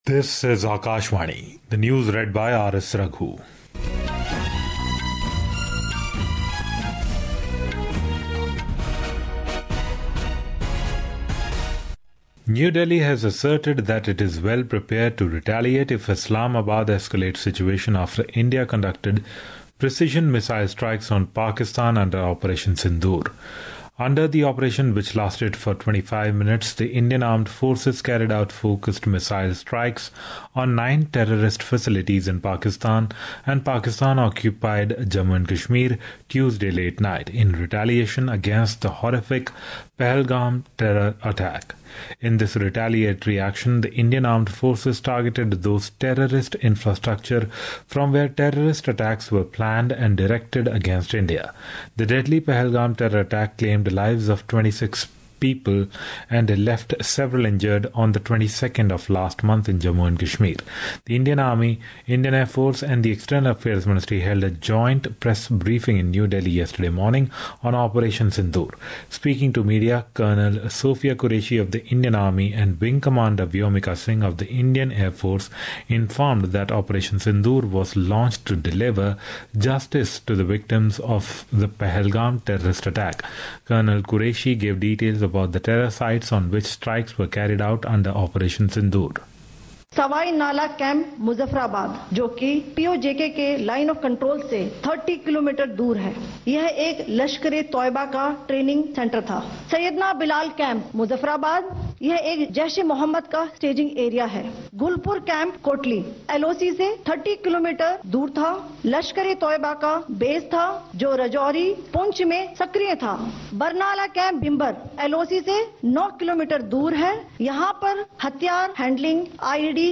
Hourly News | English